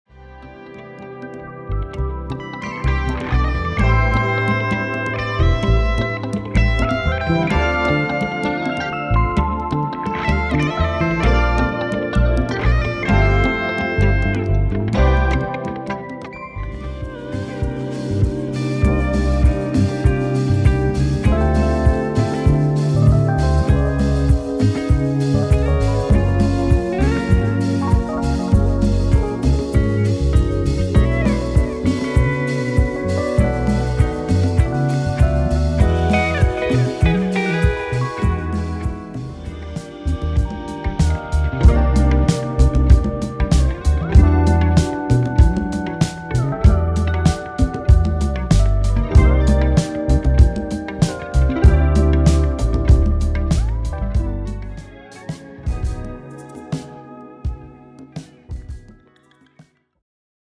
Latin American